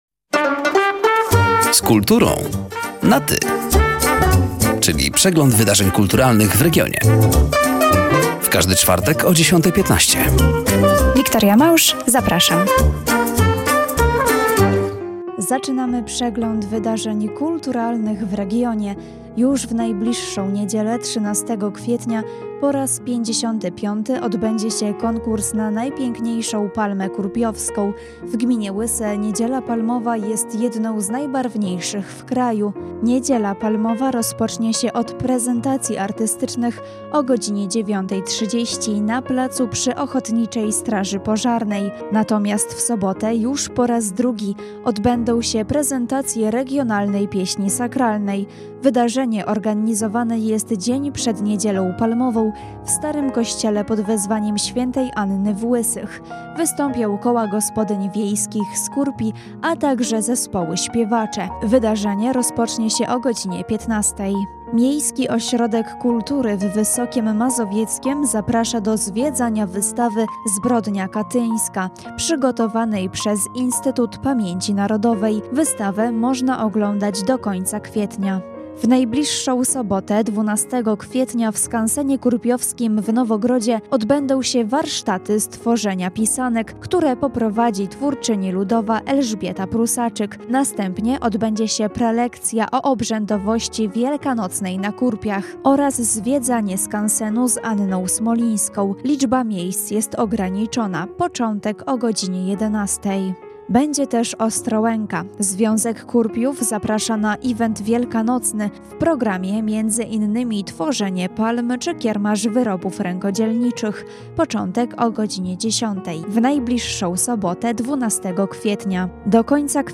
,,Z kulturą na Ty” na antenie Radia Nadzieja w każdy czwartek o 10.15.